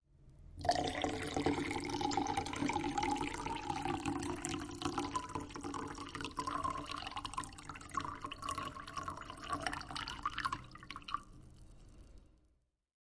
描述：水被倒入一杯。 用Zoom H1记录。
Tag: 饮料 玻璃 倾倒 饮料 液体 浇注